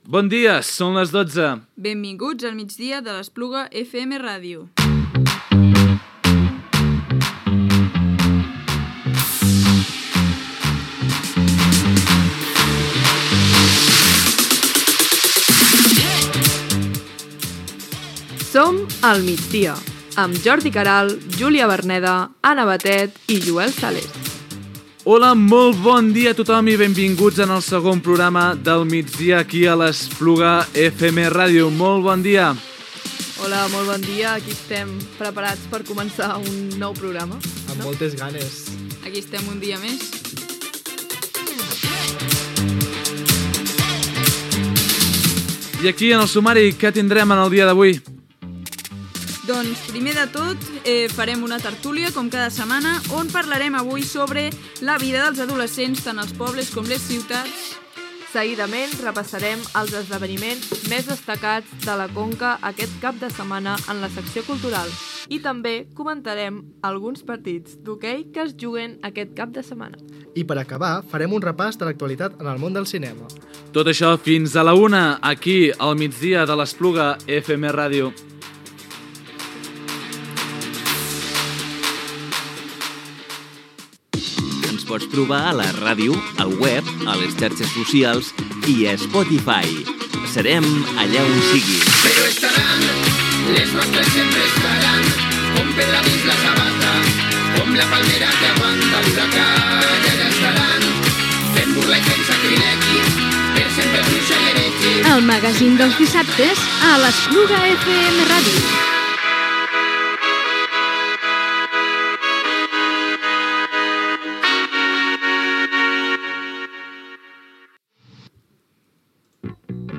5f99a85009f01af9a6a0d95f5fa67be6a9c86f4c.mp3 Títol Espluga FM Ràdio Emissora Espluga FM Ràdio Titularitat Pública municipal Nom programa El migdia de l'Espluga FM Ràdio Descripció Identificació de l'emissora i del programa, sumari, llocs per on escoltar el programa i identificació, titulars (fira de la cervesa, acolliment de famílies ucraïneses, etc.), el jovent que marxa dels pobles i què poden fer a l'Espluga de Francolí. Gènere radiofònic Infantil-juvenil
Banda FM